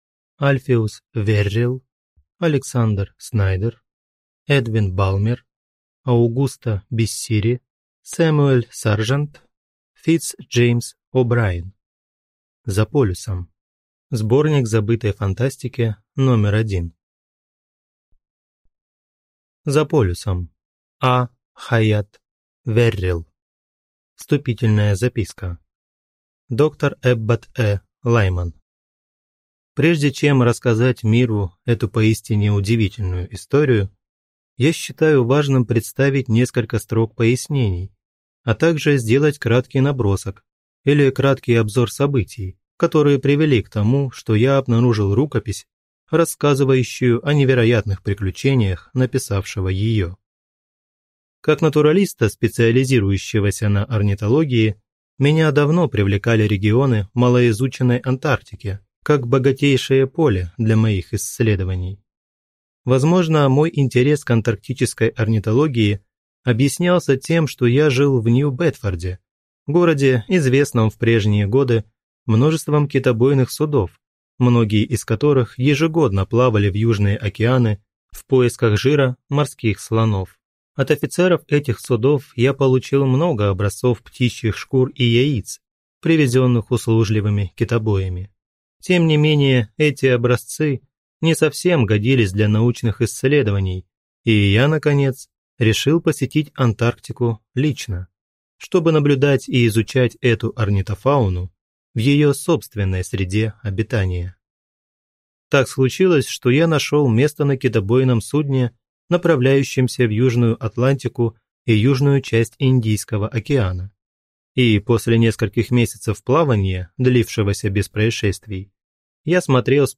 Аудиокнига Сборник забытой фантастики №1. За полюсом | Библиотека аудиокниг